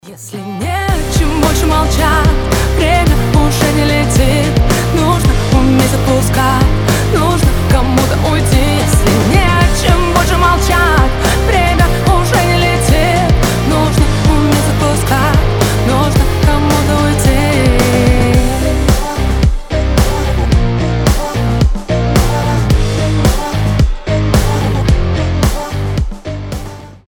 • Качество: 320, Stereo
Pop Rock
красивый женский голос